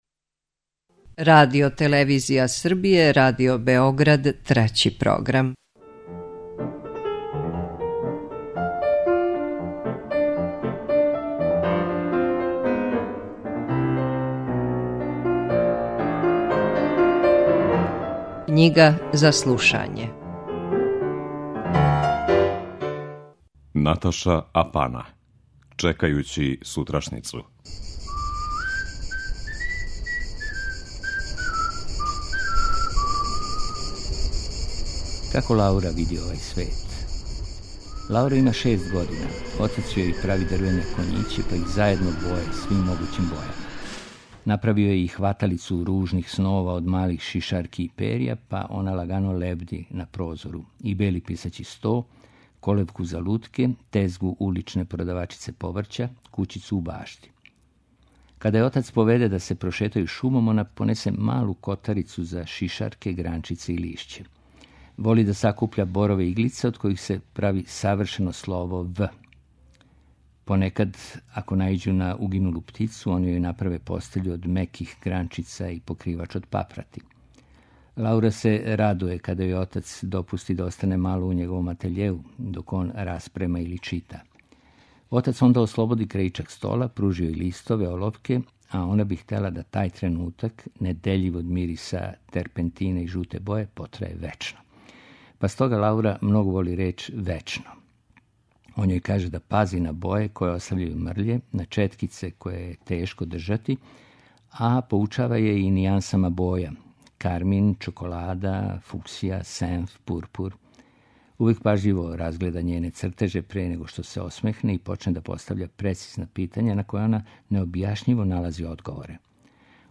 У емисији Књига за слушање можете пратити једанаести наставак романа „Чекајући сутрашњицу”, који је написала Наташа Апана, француска књижевница пореклом са Маурицијуса.